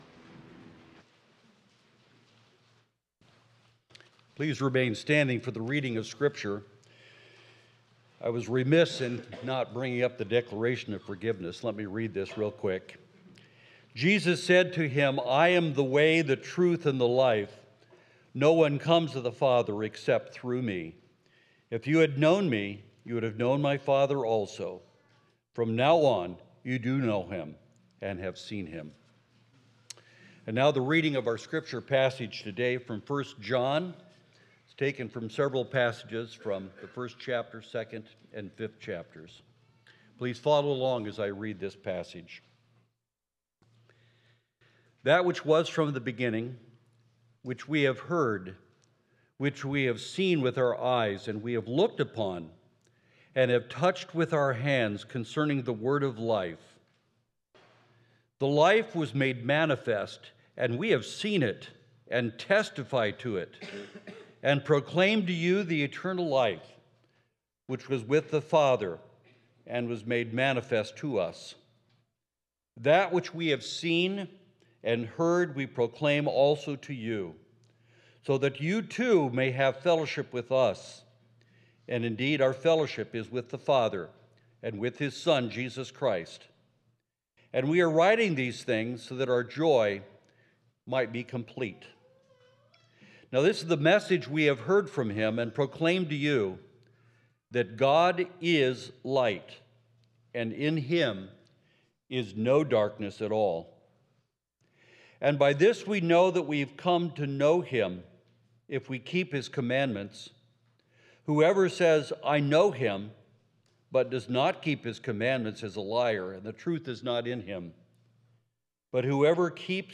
10.5.25 Sermon.m4a